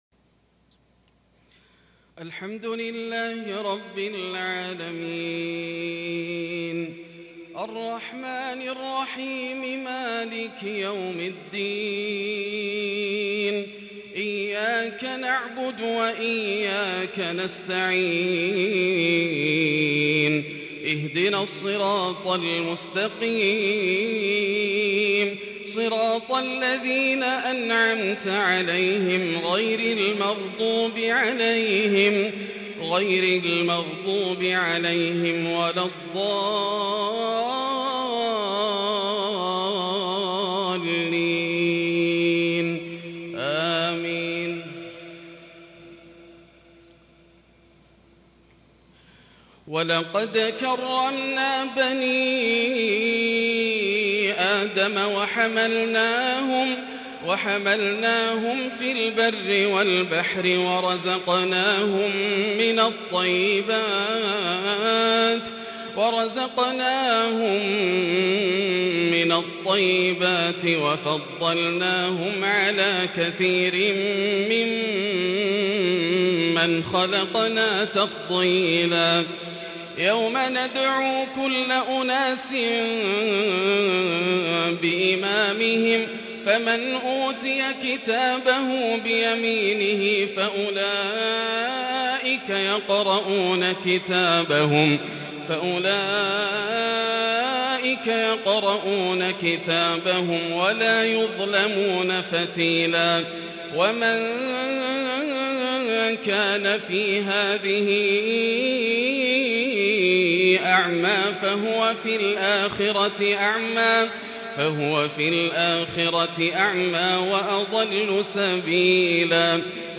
تلاوة بياتية تبهر الأسماع من سورتي الإسراء والأحزاب - فجر السبت 3-1-1442 > عام 1442 > الفروض - تلاوات ياسر الدوسري